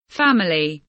family kelimesinin anlamı, resimli anlatımı ve sesli okunuşu